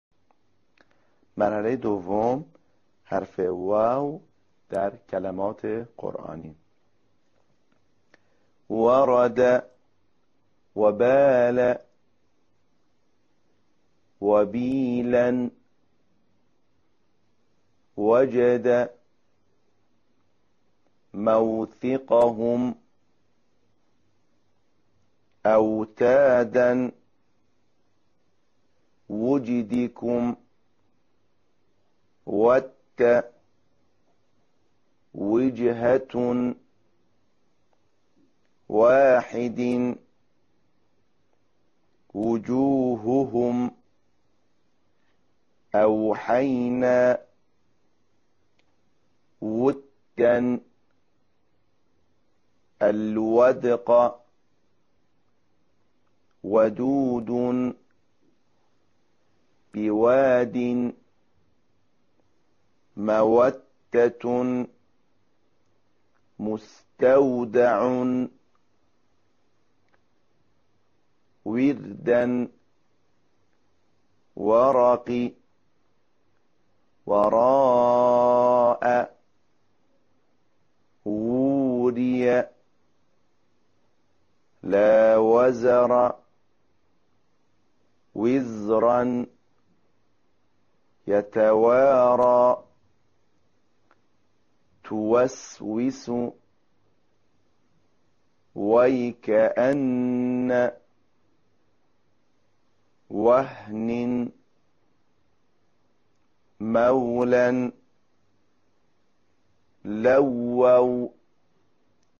این حرف از بین دو لب، بدون دخالت دندان ها به تلفظ در می‌آید.
تمرین عملی_مرحله ۲
💠تلفظ حرف «و»💠